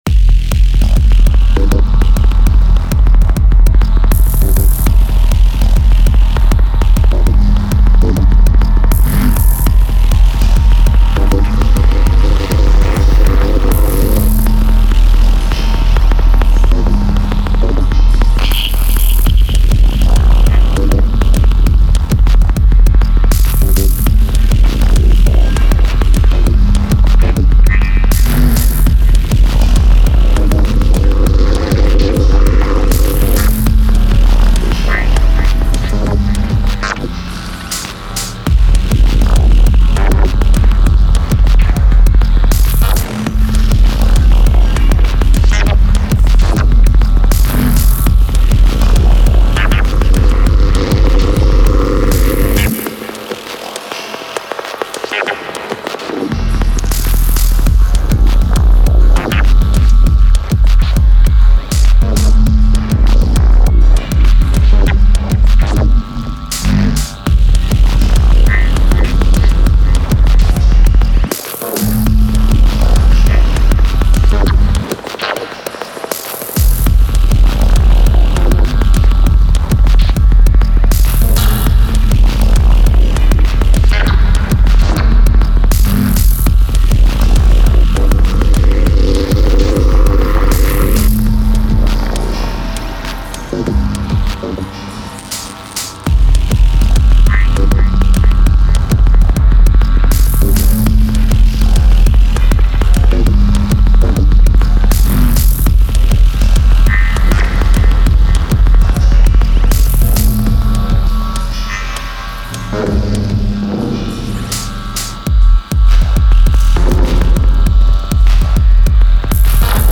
Main stuff from Tonverk, some drum slices from Digitakt, fx sounds from Digitone runned through tonverk